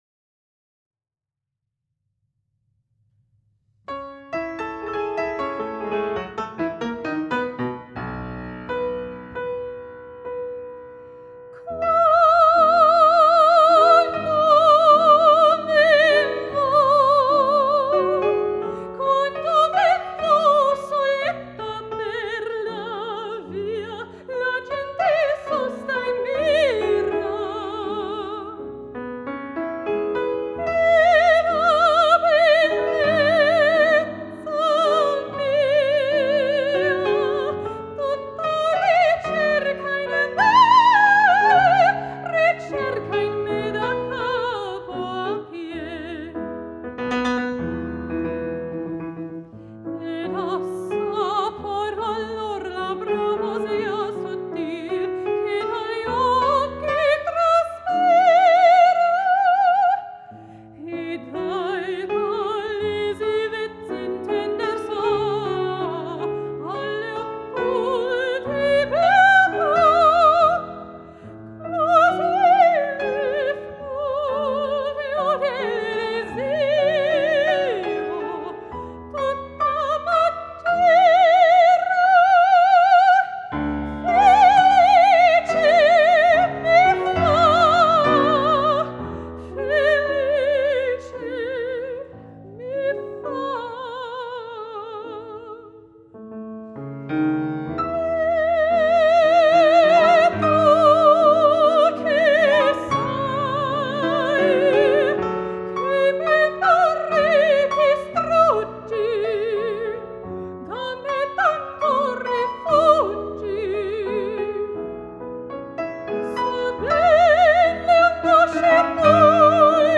Boston Opera Singer 4
Denver-Opera-Singer-1-Quando-men-1.mp3